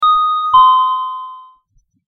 Elevator Ding
Elevator_ding.mp3